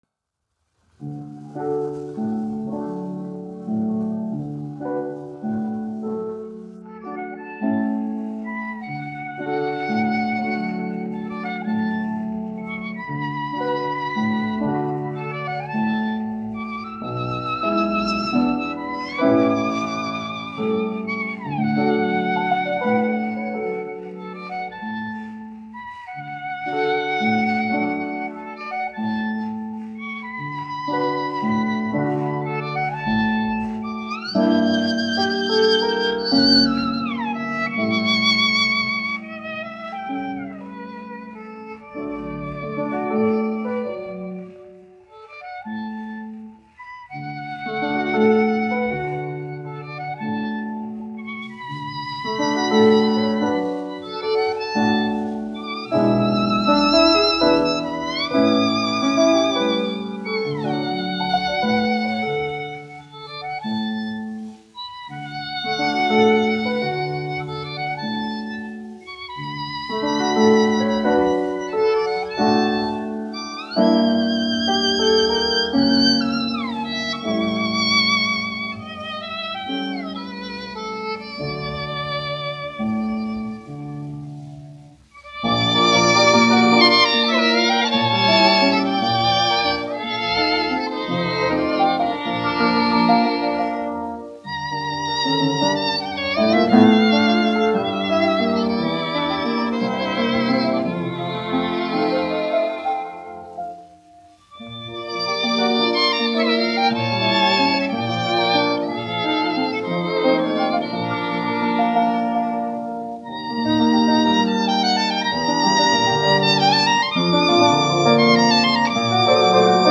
1 skpl. : analogs, 78 apgr/min, mono ; 25 cm
Vijoles un klavieru mūzika
Skaņuplate